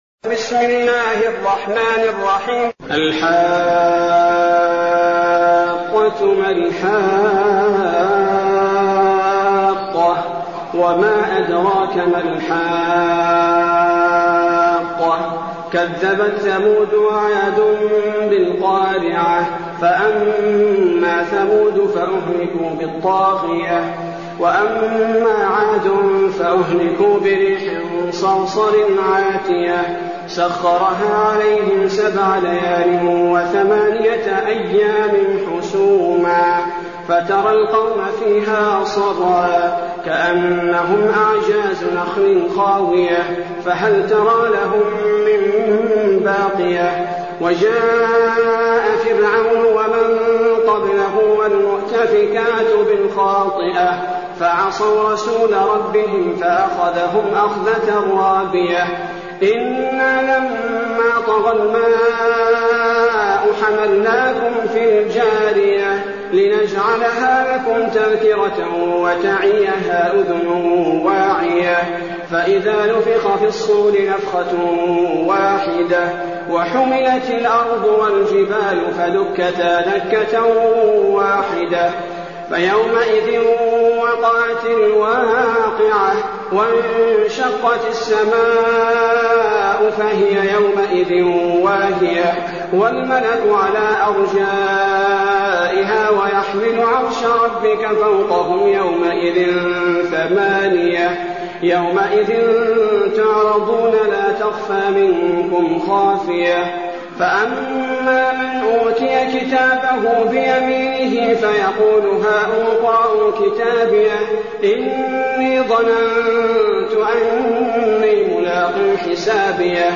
المكان: المسجد النبوي الشيخ: فضيلة الشيخ عبدالباري الثبيتي فضيلة الشيخ عبدالباري الثبيتي الحاقة